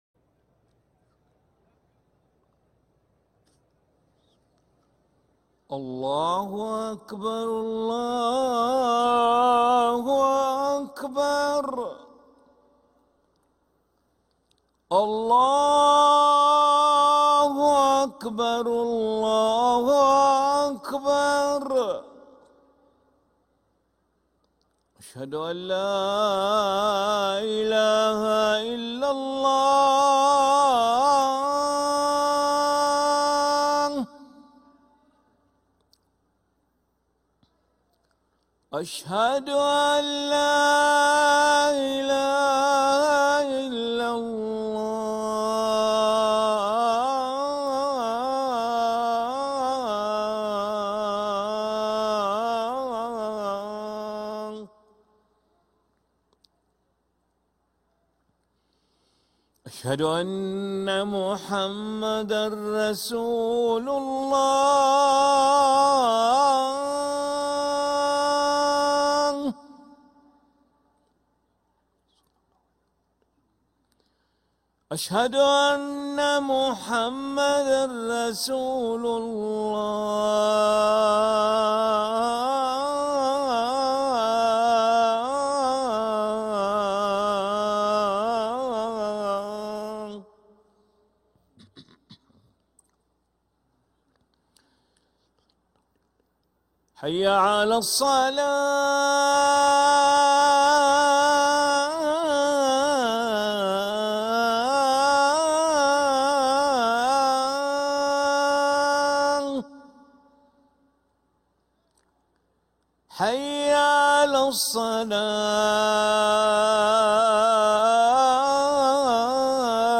أذان العشاء للمؤذن علي ملا الأحد 26 ربيع الأول 1446هـ > ١٤٤٦ 🕋 > ركن الأذان 🕋 > المزيد - تلاوات الحرمين